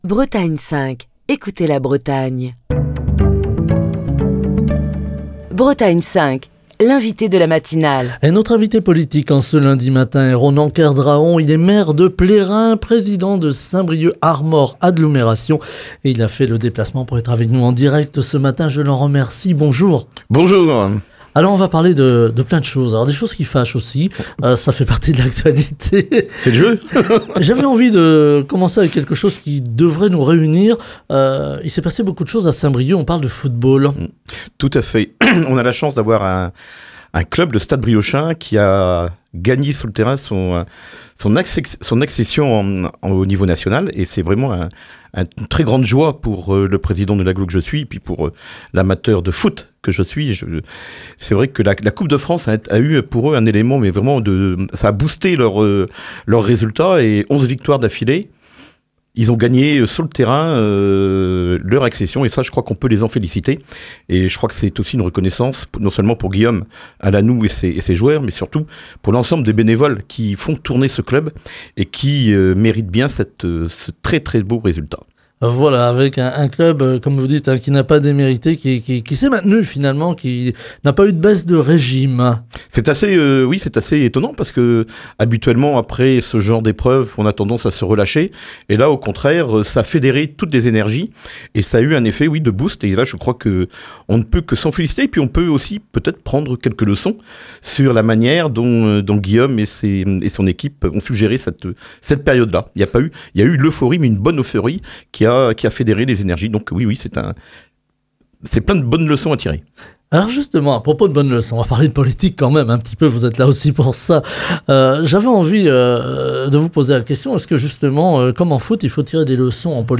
Ce lundi, Ronan Kerdraon, maire de Plérin et président de Saint-Brieuc Armor Agglomération, était l’invité politique de la matinale de Bretagne 5. Avant d’aborder les sujets d’actualité, l’élu est revenu sur l’exploit du Stade Briochin, promu en National à l’issue d’une saison exceptionnelle.